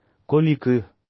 Komi (коми кыв, komi kyv, IPA: [komi kɨv]
Kv-komi_kyv.wav.mp3